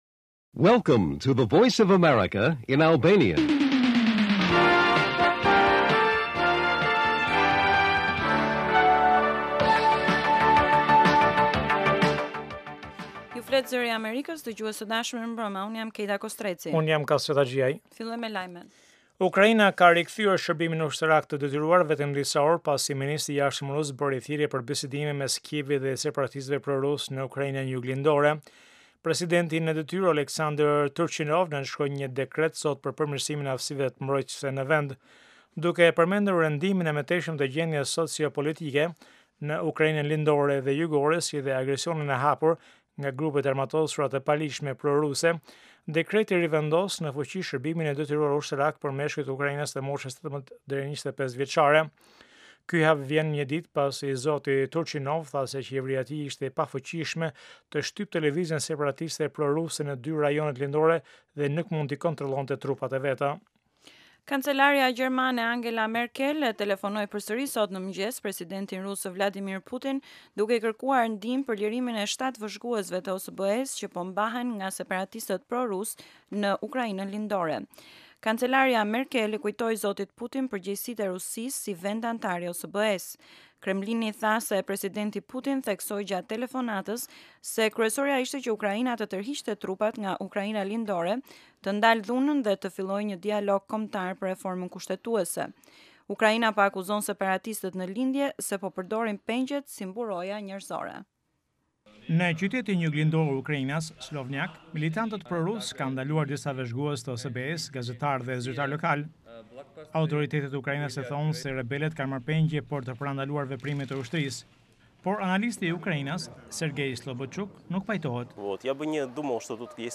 Lajmet e mbrëmjes